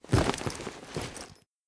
looting_8.ogg